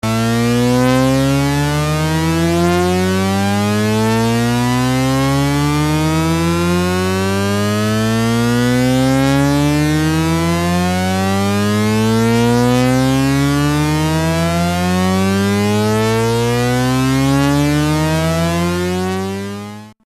Each timbre uses two mod seqencers, the upper for the pitch and the second for the amp level.
Due the range limitation in the mod values for the pitch -24 to 24 the sweep has only 4 octaves. The four timbrers have the same setting: Osc1=Saw with no other setting.
My mind keeps wanting to think the sound restarts but then it listens and feels like it's a continuous linear upward motion, so then I listen again and my mind think it's restarting again. >.>
EndlessPad.mp3